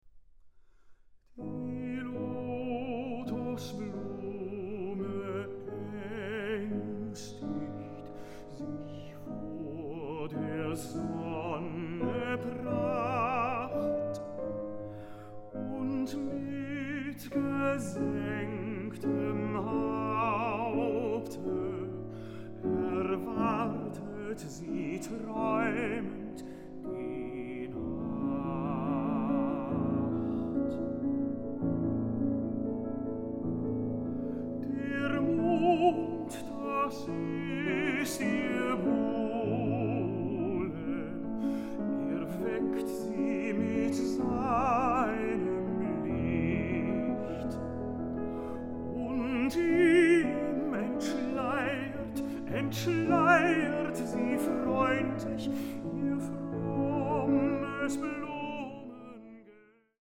soprano
tenor
piano